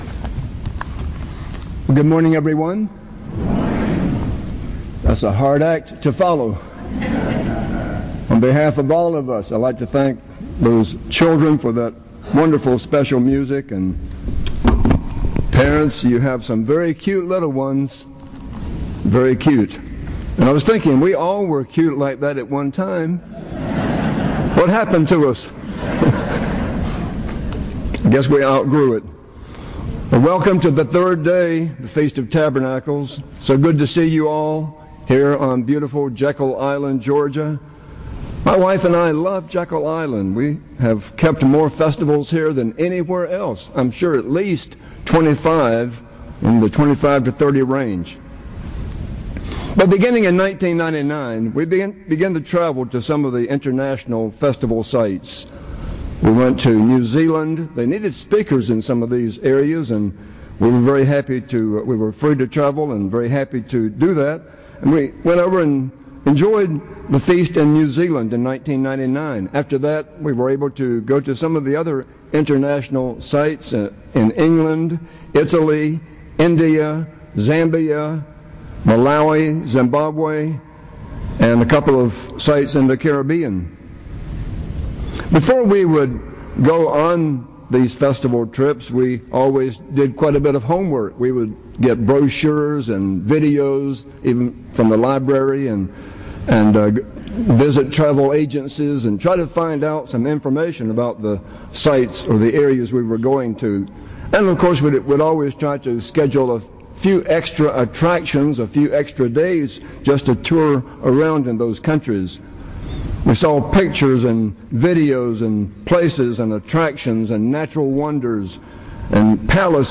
This sermon was given at the Jekyll Island, Georgia 2018 Feast site.